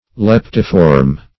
Search Result for " leptiform" : The Collaborative International Dictionary of English v.0.48: Leptiform \Lep"ti*form\ (-t[i^]*f[^o]rm), a. [Leptus + -form.]